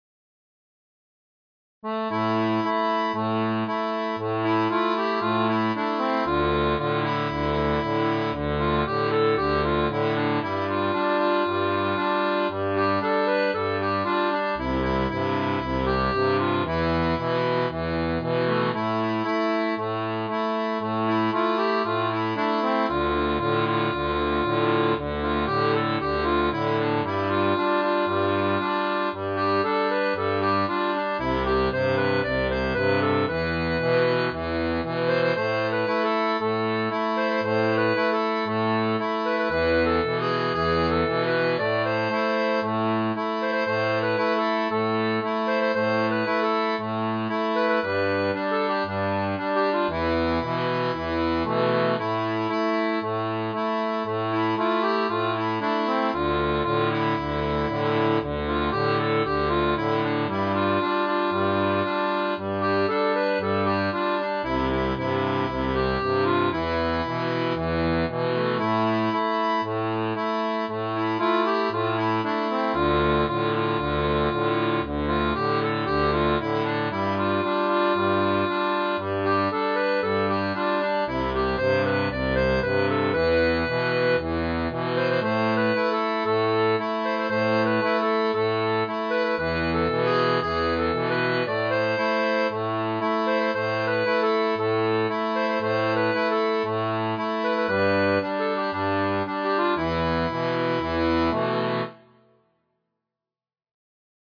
• Une tablature pour diato à 2 rangs (jeu poussé-tiré)
Chanson française